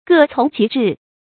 各从其志 gè cóng qí zhì 成语解释 从：听任；志：志向。
成语繁体 各従其志 成语简拼 gcqz 成语注音 ㄍㄜˋ ㄘㄨㄙˊ ㄑㄧˊ ㄓㄧˋ 常用程度 常用成语 感情色彩 中性成语 成语用法 动宾式；作谓语；指各人依照各人的意志行事 成语结构 主谓式成语 产生年代 古代成语 近 义 词 各行其志 成语例子 如以实权为难舍，以虚号为可娱，则解释法律，正复多端，亦 各从其志 而已。